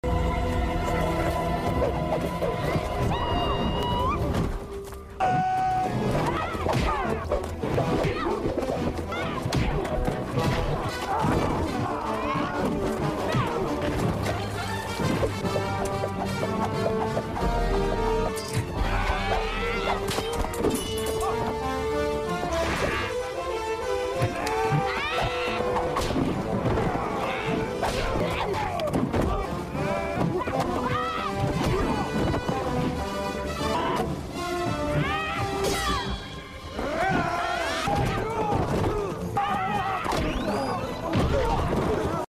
Грохот схватки Зены с злодеями